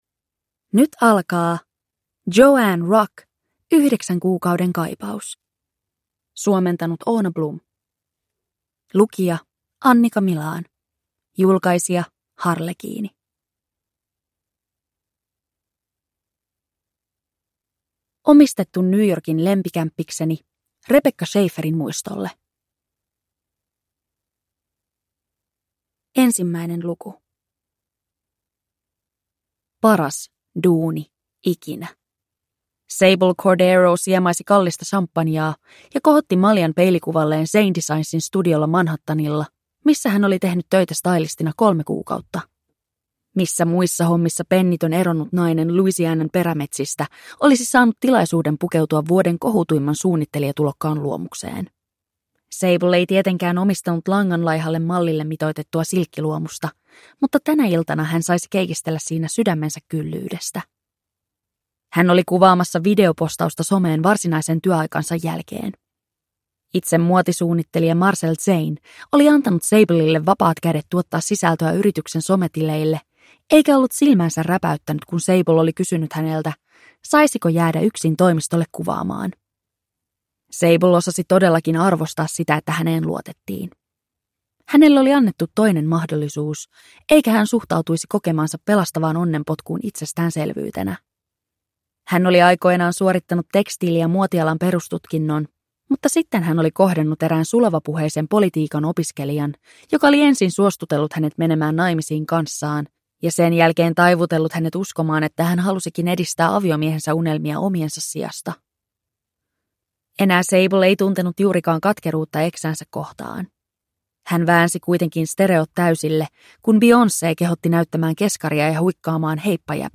Yhdeksän kuukauden kaipaus – Ljudbok